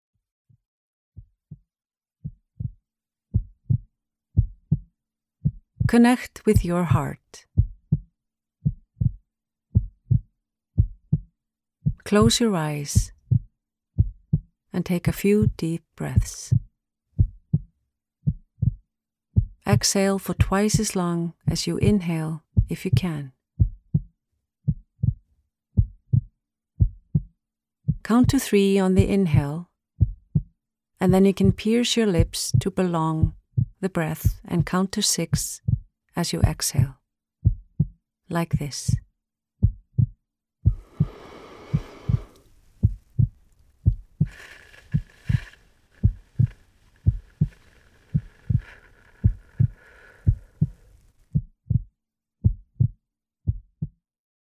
03-Connect-with-your-Heart_-Meditation.mp3